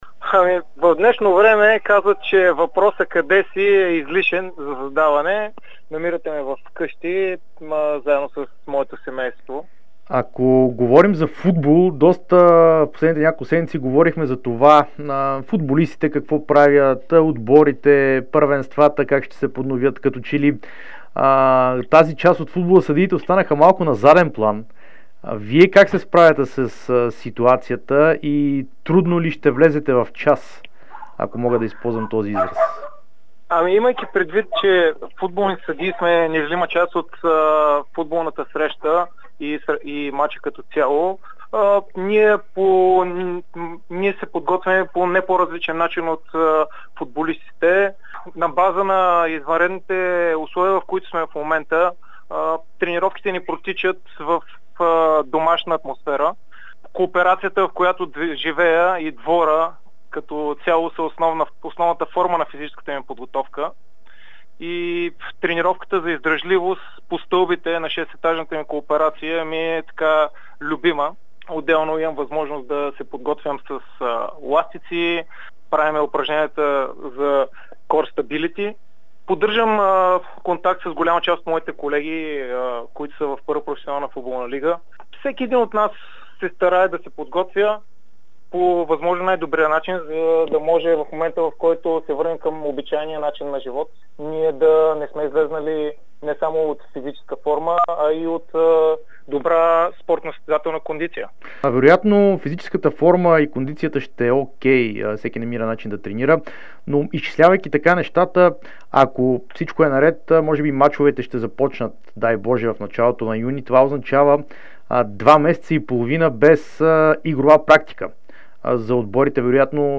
Българският съдия Георги Кабаков даде специално интервю за Дарик и dsport, в което говори за въвеждането на системата за видеоповторения у нас, амбицията му да свири на Европейското първенство, както и трудните решения, които е трябвало да взема на терена.